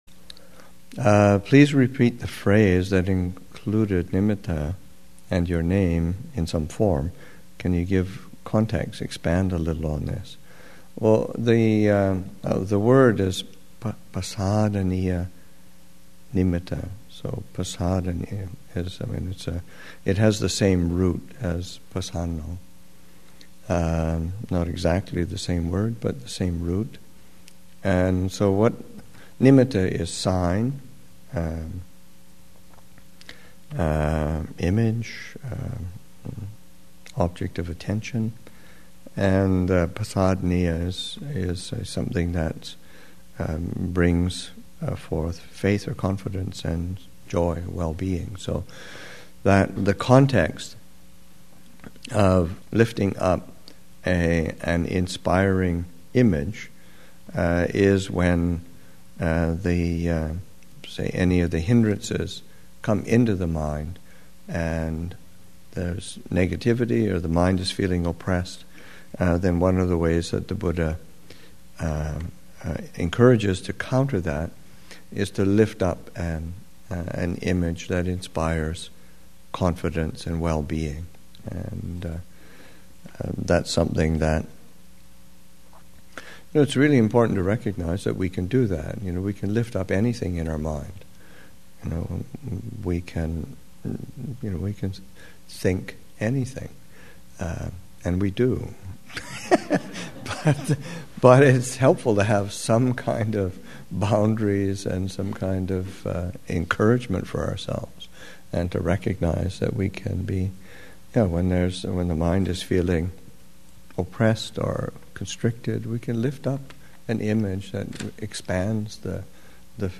Thanksgiving Retreat 2016, Session 4 – Nov. 22, 2016